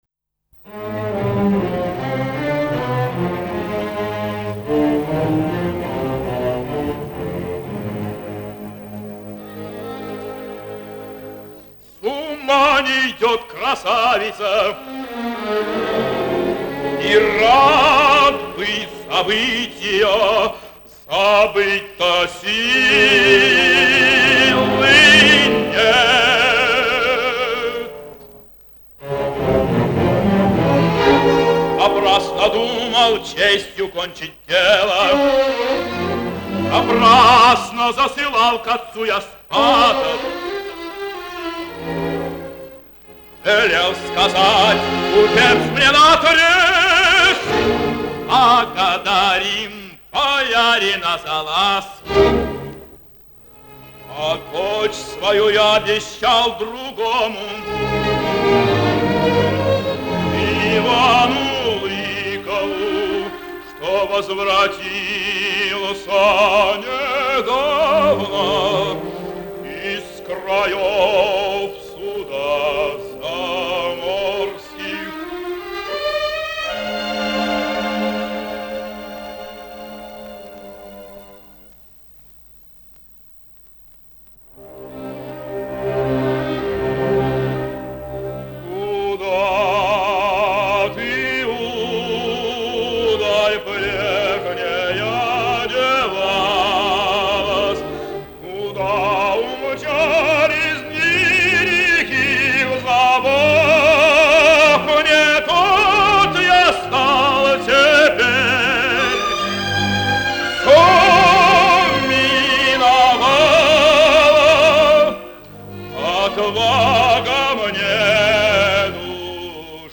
08 - Сергей Мигай - Ария Грязного (Н.А.Римский-Корсаков. Царская невеста, 1 д.) (1937)